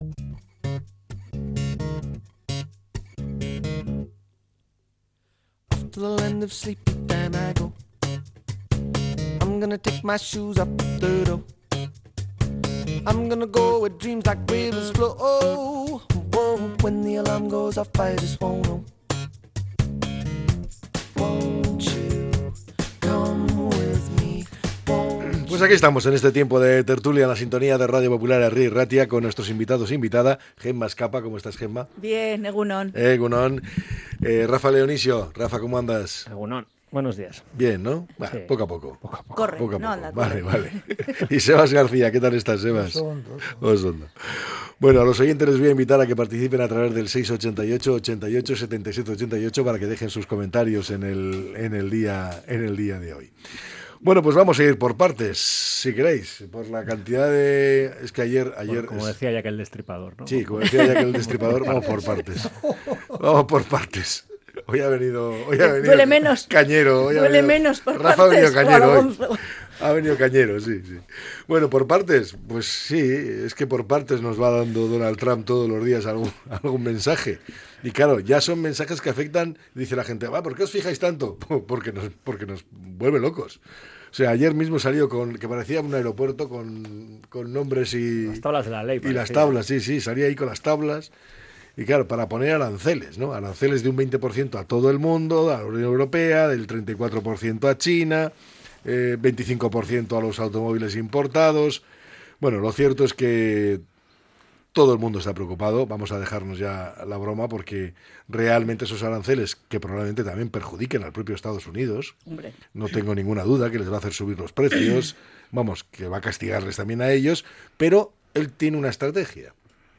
La tertulia 03-04-25.